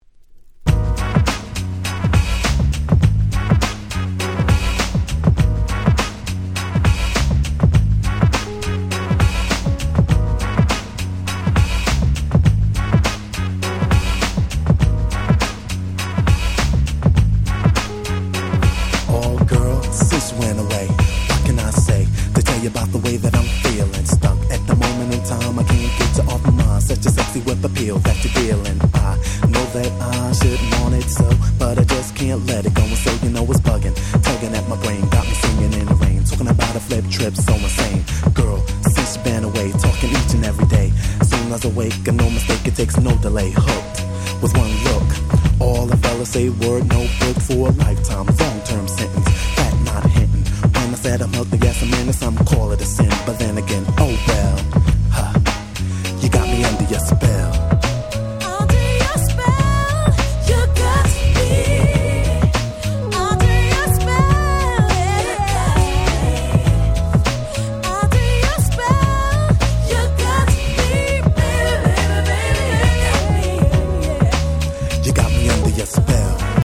93' Nice Acid Jazz / UK Soul !!
爽やかなギターの音色と切ない女性ボーカルが完璧！！